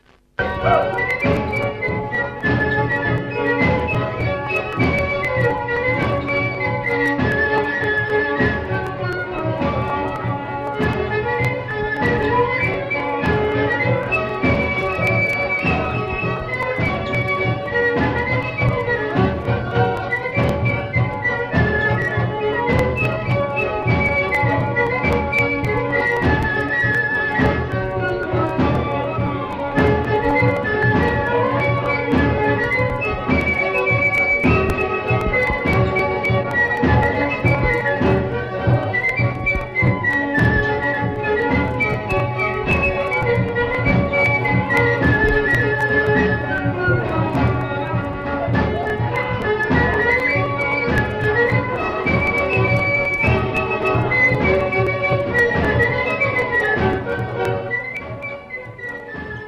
Aire culturelle : Grandes-Landes
Lieu : Garein ; Liposthey
Genre : morceau instrumental
Instrument de musique : fifre ; violon ; tambour
Danse : kaxkarot